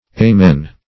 Amen - definition of Amen - synonyms, pronunciation, spelling from Free Dictionary
Amen \A`men"\, v. t.